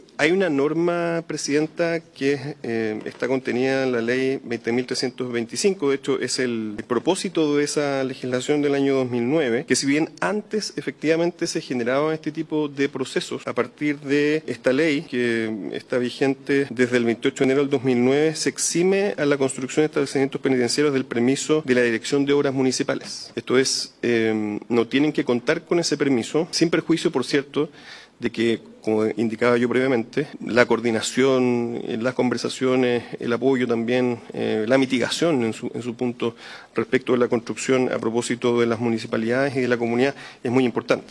El subsecretario de Justicia, Ernesto Muñoz, explicó que desde 2009 la ley 20.325 exime a la construcción de recintos penitenciarios de contar con permisos de edificación de las direcciones de obras municipales, aunque subrayó la importancia de mantener la coordinación con las comunidades y autoridades locales.